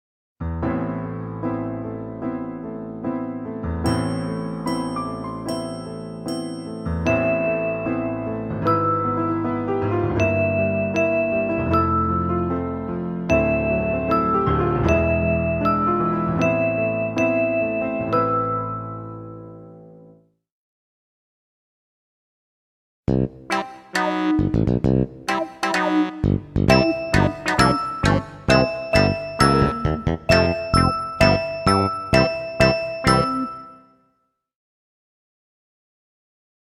Extra soundmixbegeleidingen voor tutors instrument: